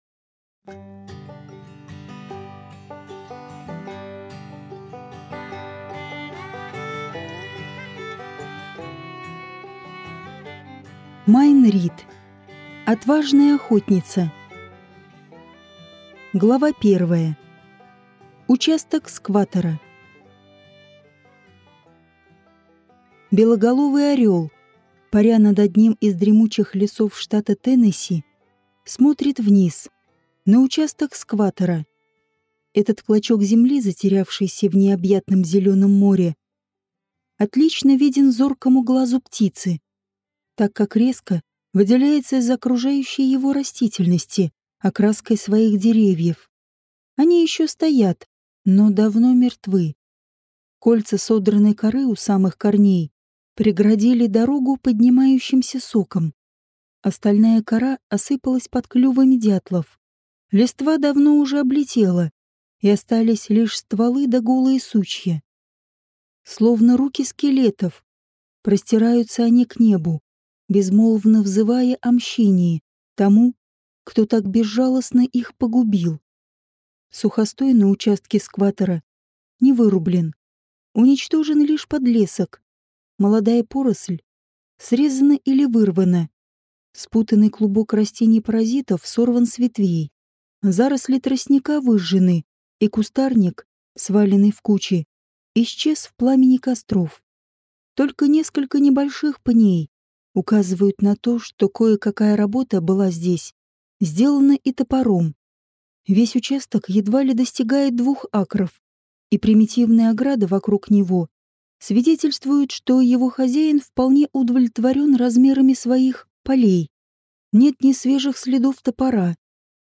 Аудиокнига Отважная охотница | Библиотека аудиокниг